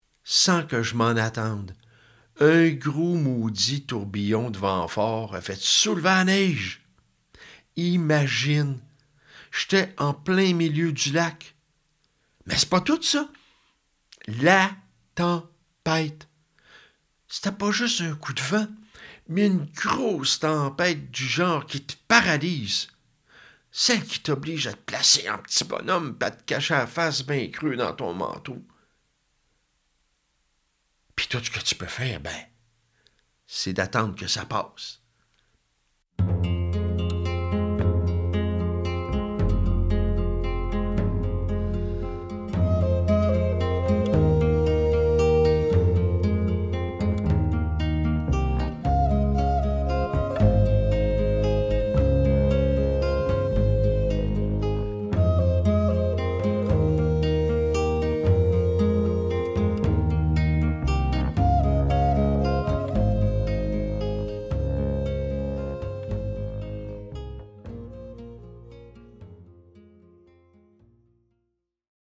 Le Tourbillon | Conte audio
🎧 Conte audio d’une durée d’environ 28 minutes, enrichi de pièces musicales originales tirées de mon album primé à l’international, intégrées à quatre moments clés du récit (début, passages centraux et conclusion), dans le respect de l’oralité et du silence des mots.